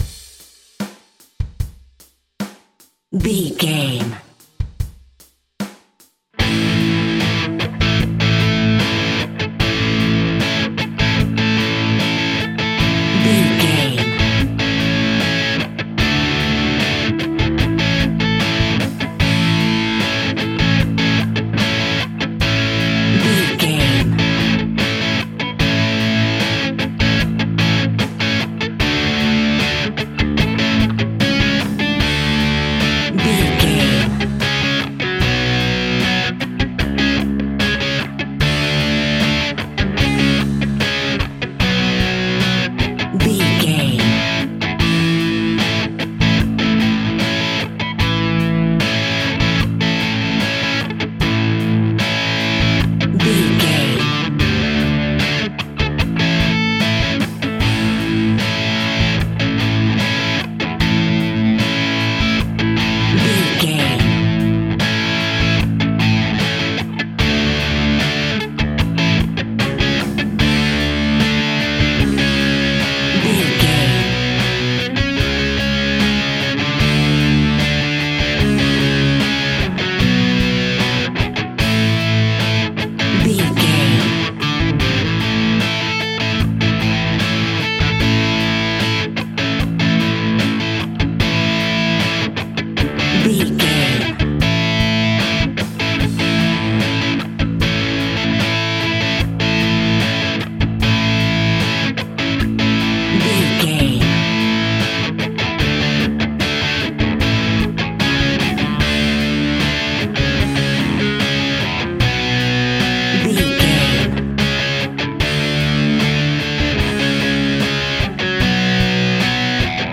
Slow Rock Music.
Ionian/Major
Fast
energetic
driving
heavy
aggressive
electric guitar
bass guitar
drums
distortion
hard rock
Instrumental rock